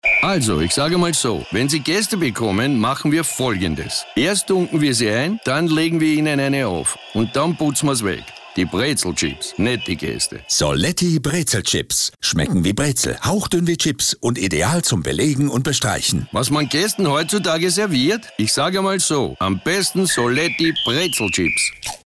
In bewährter Weise wird Testimonial Herbert Prohaska in einem Radio-Spot mit Augenzwinkern seinen Gästen neue Verzehranlässe für Soletti BrezelChips schmackhaft machen.